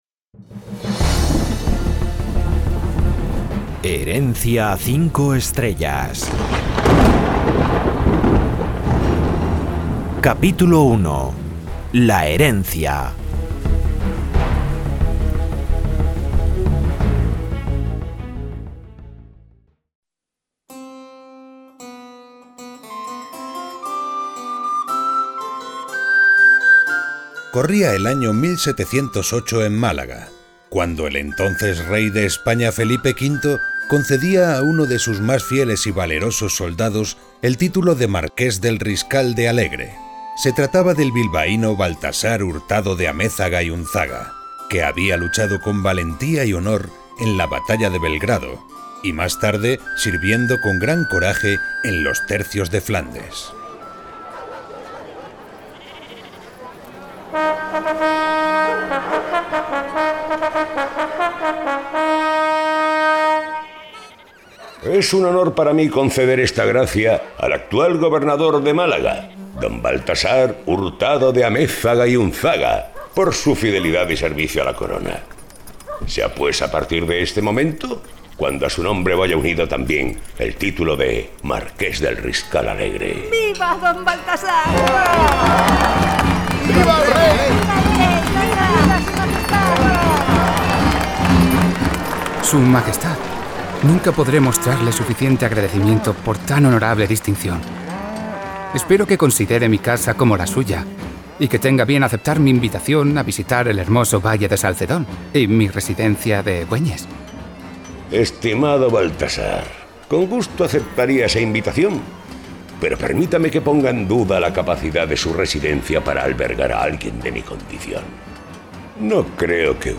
Vozex - Radioteatro Herencia 5 Estrellas